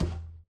Sound / Minecraft / mob / irongolem / walk3.ogg
walk3.ogg